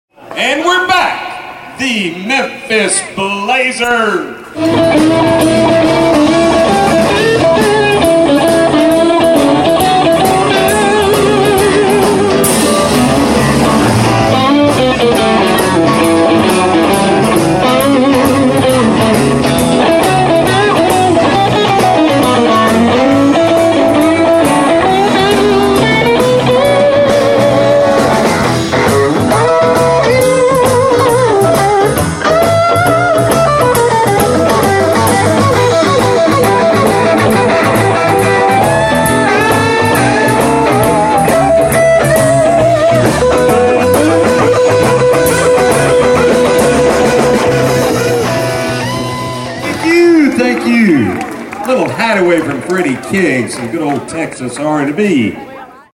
Live at Shangri-la